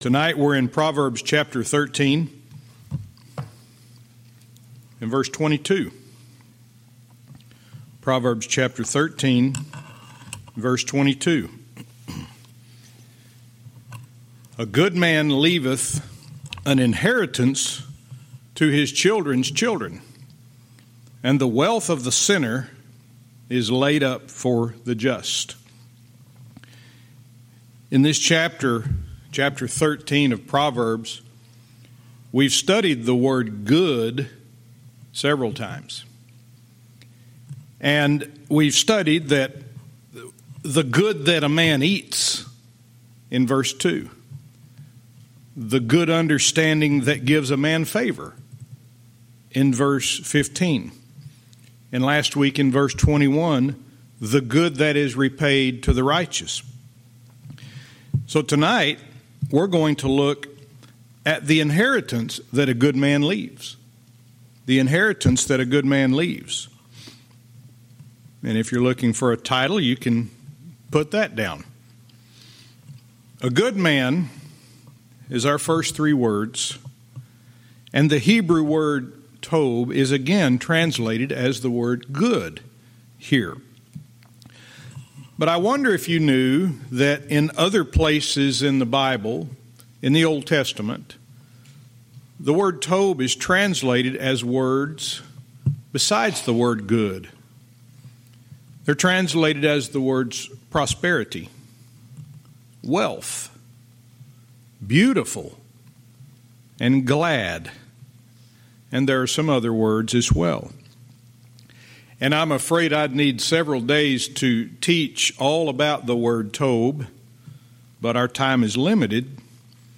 Verse by verse teaching - Proverbs 13:22